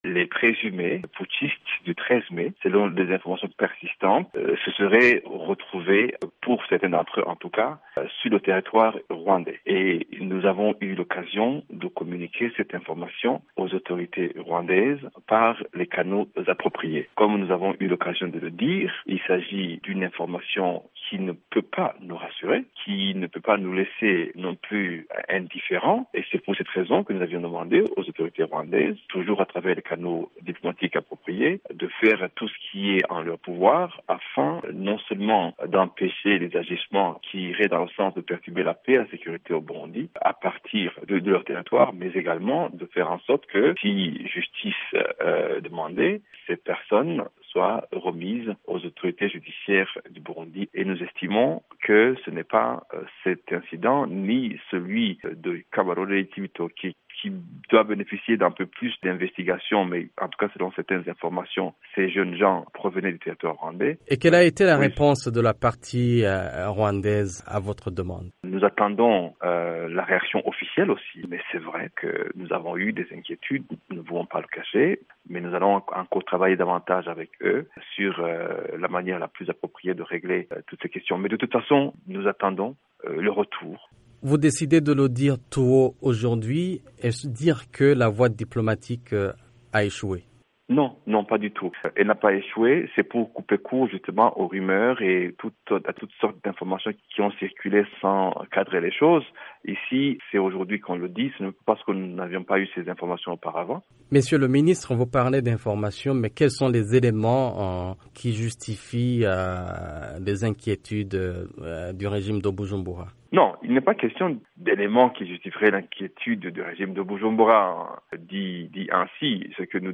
Alain Nyamitwe, ministre burundais des Affaires étrangères.